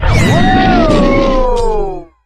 Cri de Pondralugon dans Pokémon HOME.